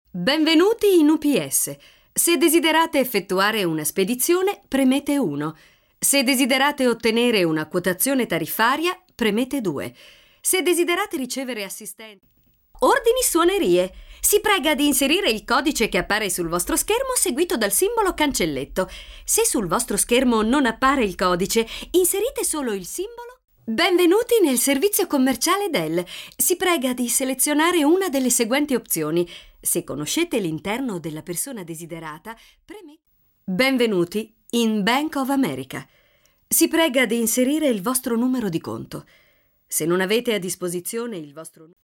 Sprechprobe: eLearning (Muttersprache):
Very adaptable voice, young, warm, pleasant, sexy, professional...